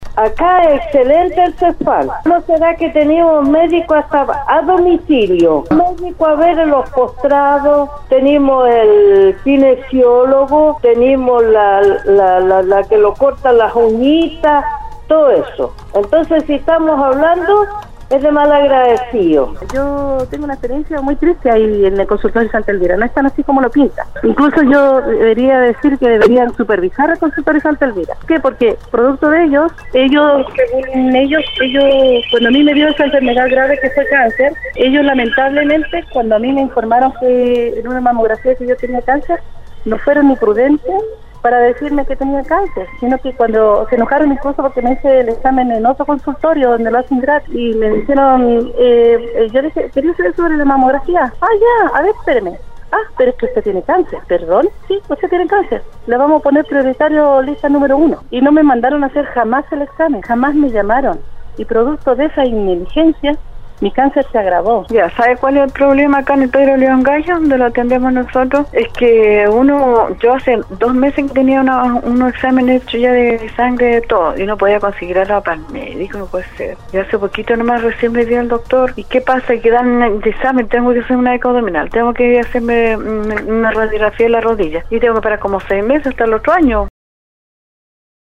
La mañana de este jueves, los auditores participaron en el foro del programa Al Día de Nostálgica donde pudieron opinar y contar su experiencia cuando van a solicitar un servicio a los distintos Centros de Salud Familiar de la Región de Atacama.
Se recibieron muchas llamadas y mensajes, sobre la atención primaria que se recibe en estos establecimientos de salud, que si bien, son básicas, no dejan de ser un desahogo para los pacientes que requieren algún tratamiento médico.